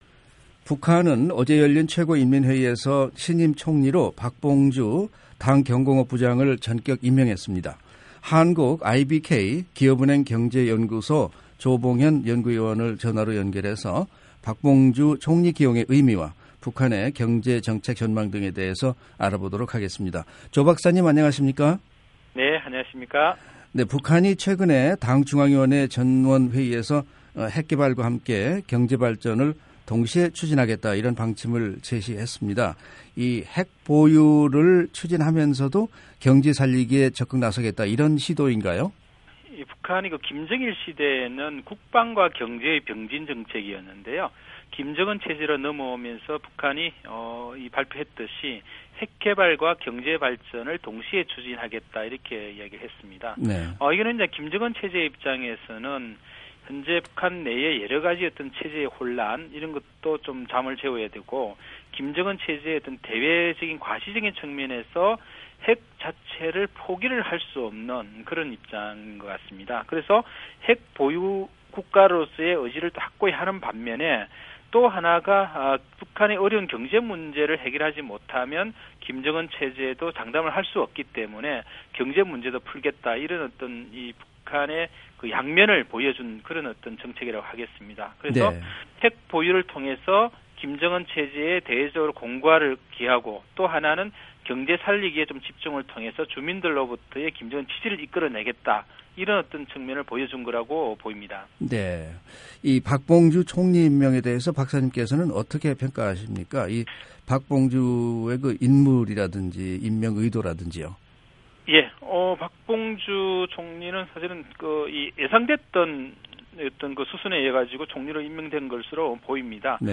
인터뷰
전화로 연결해